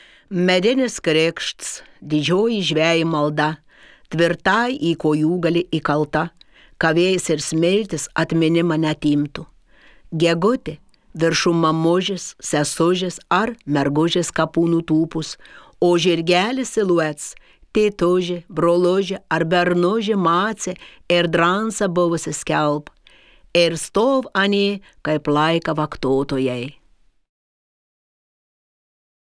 Pasiklausyk šišioniškai